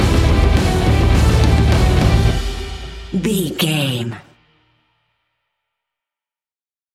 Epic / Action
Fast paced
Aeolian/Minor
hard rock
Heavy Metal Guitars
Metal Drums
Heavy Bass Guitars